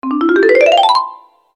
Fast Ascending Marimba Arpeggio Sound Effect
Description: Fast ascending marimba arpeggio sound effect. A cheerful and funny marimba arpeggio sound effect perfect for games, cartoons, and apps. Use this playful, fast-ascending melody to highlight wins, level-ups, or joyful moments in your project.
Genres: Sound Effects
Fast-ascending-marimba-arpeggio-sound-effect.mp3